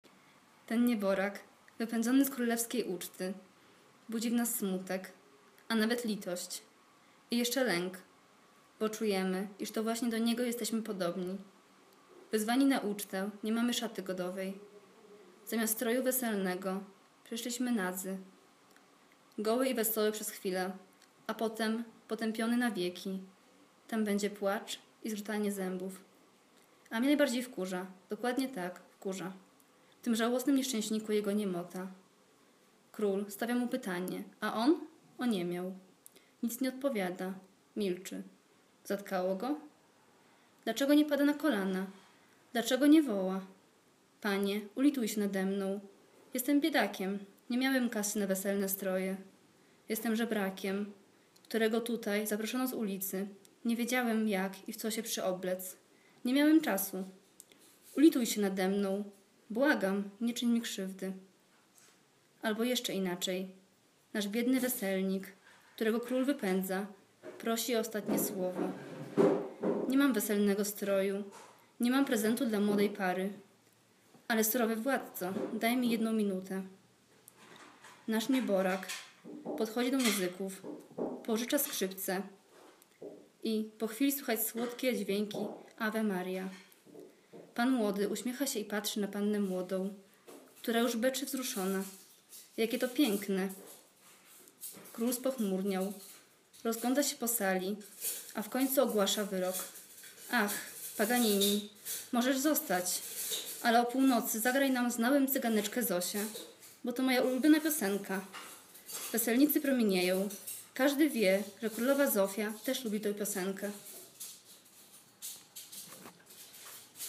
wakacyjne warsztaty studentów KUL w Zakopanem